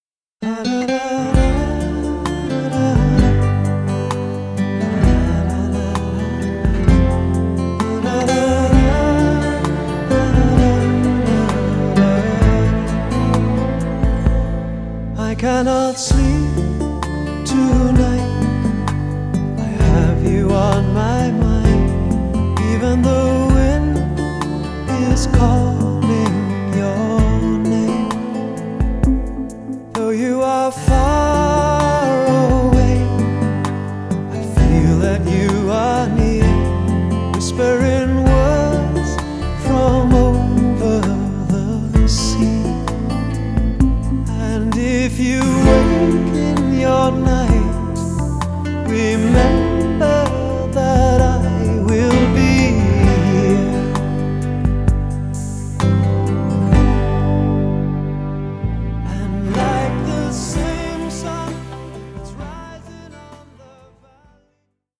• Качество: 320, Stereo
красивые
лирика
спокойные
романтичные